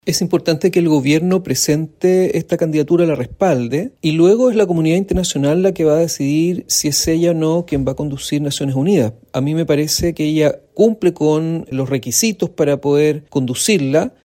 El diputado del Partido Ecologista Verde, Félix González, respaldó la candidatura de Michelle Bachelet y aseguró que es la comunidad internacional la que decida si es la mejor carta para liderar Naciones Unidas.